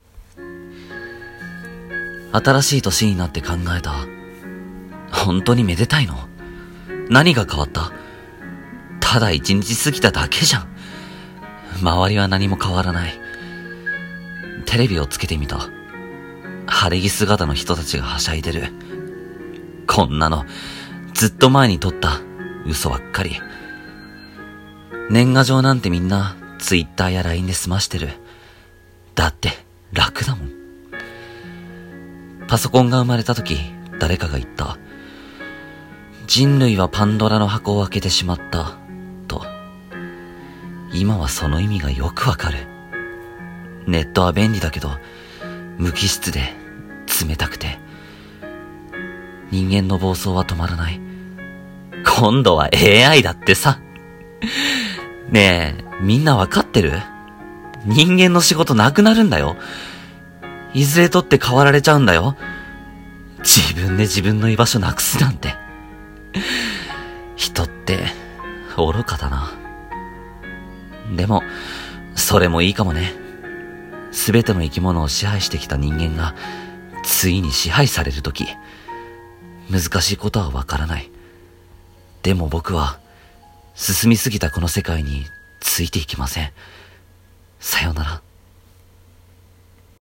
【1人 声劇台本】「退廃」